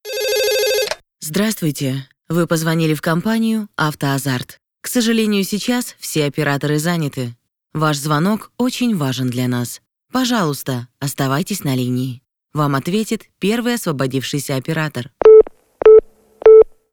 Студия звукозаписи: помещение с шумоизоляцией, микрофон, звуковая карта, студийные мониторы.